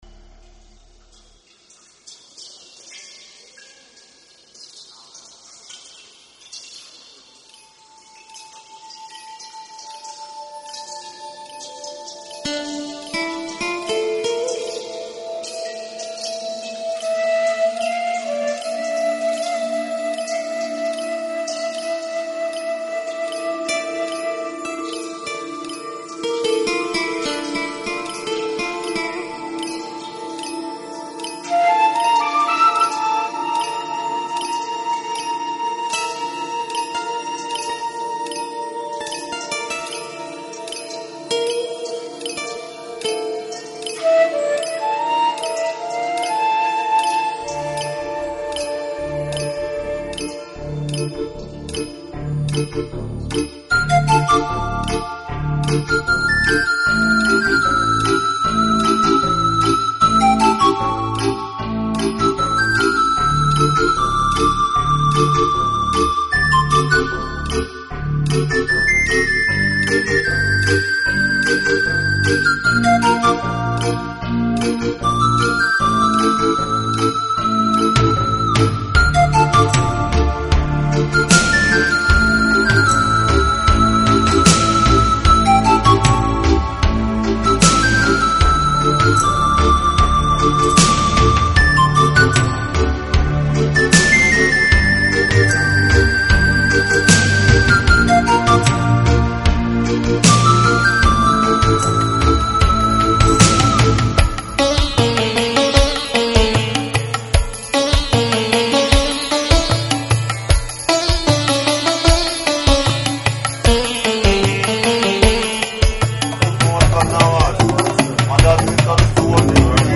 弱一点，但更显轻快平和，象天边的那一抹微云，悠远而自在。